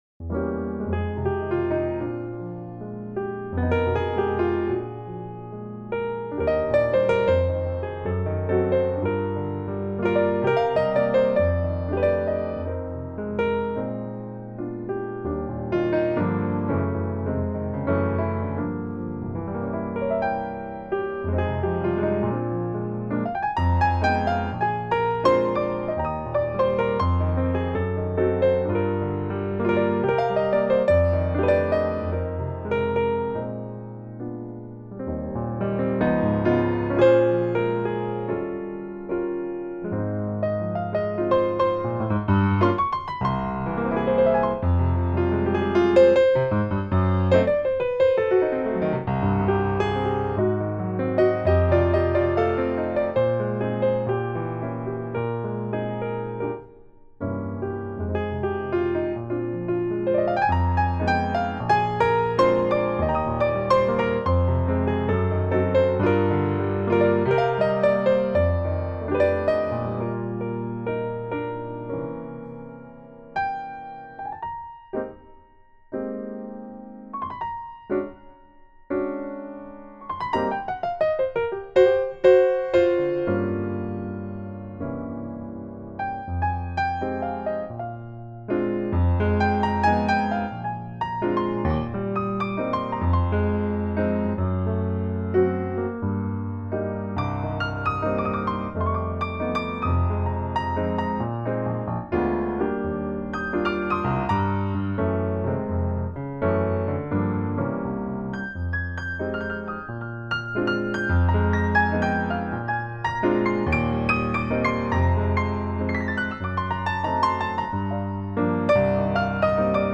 Piano Demos